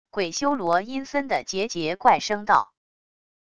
鬼修罗阴森的桀桀怪声道wav音频